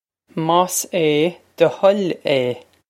Pronunciation for how to say
mawsh ey duh huh-ill ey
This is an approximate phonetic pronunciation of the phrase.